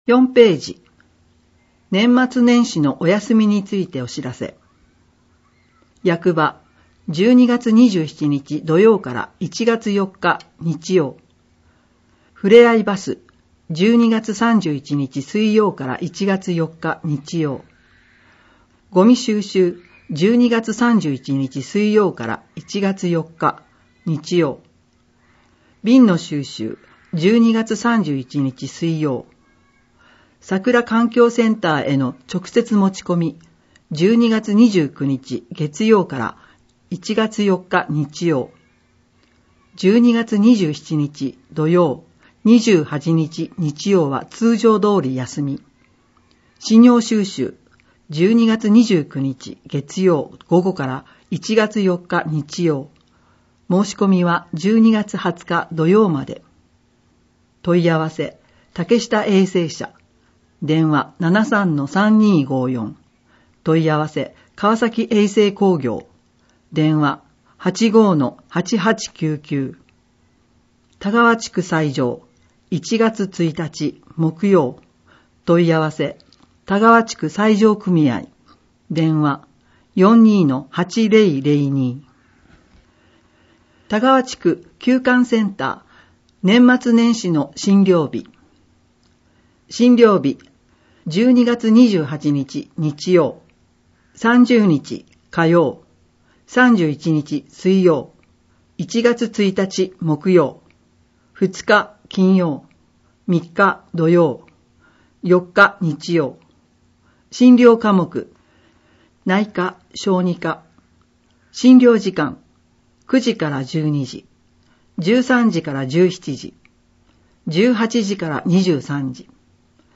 『広報かわさき』を音訳している川崎町朗読ボランティア「ひまわり」の作成した音声データを掲載しています。